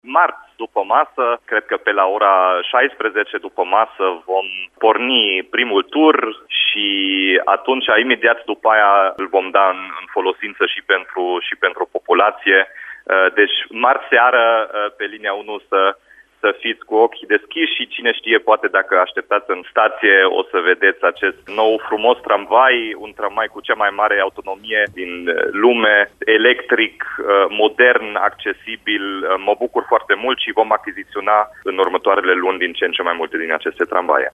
Primarul Dominic Fritz a declarat, la Radio Timișoara, că primele curse de călători ale noii garnituri sunt prevăzute pentru seara zilei de marți, 14 decembrie, pe linia 1.